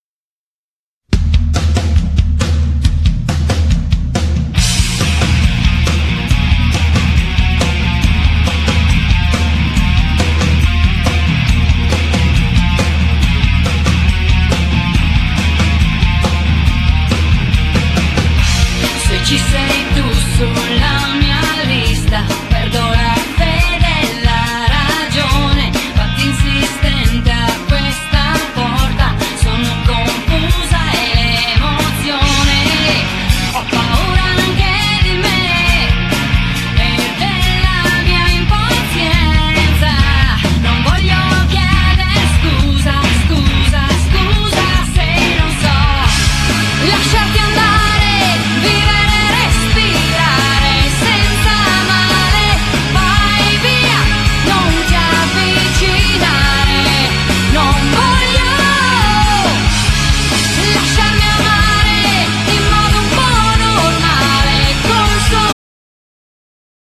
Genere : Pop
dal sound accattivante e coinvolgente